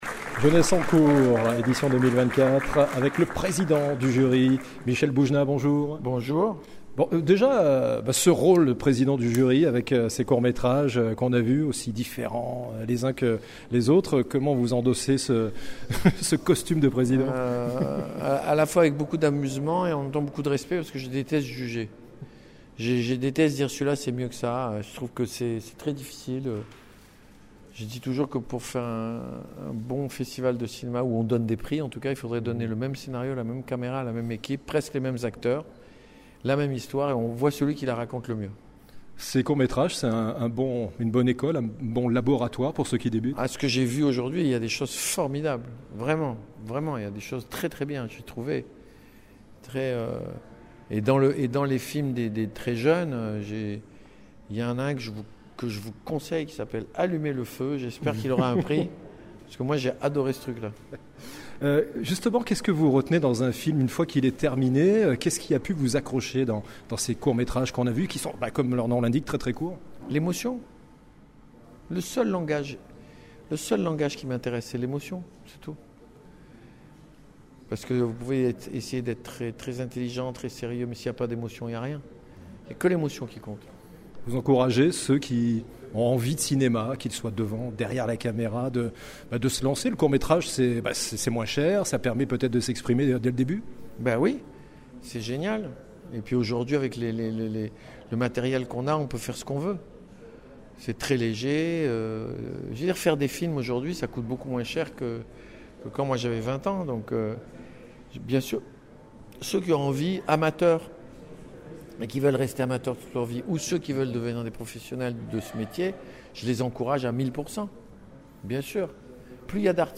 Interview réalisée à l'occasion du 3ème festival "jeunesse en court"